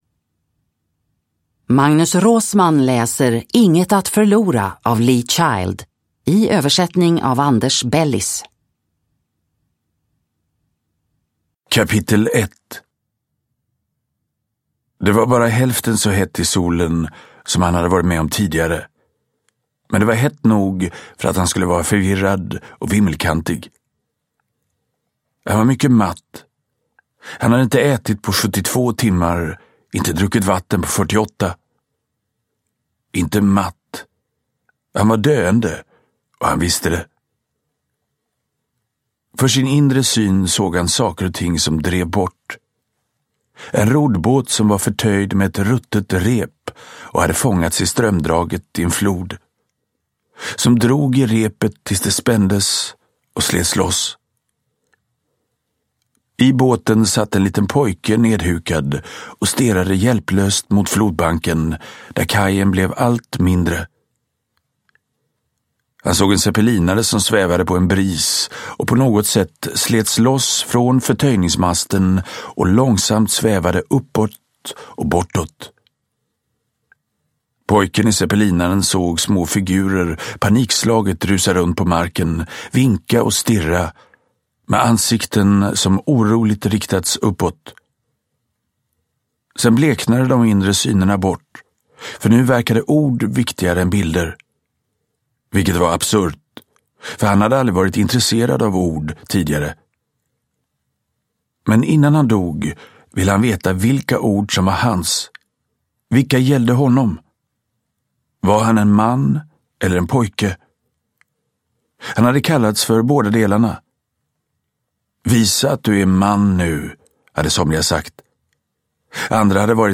Inget att förlora – Ljudbok – Laddas ner
Uppläsare: Magnus Roosmann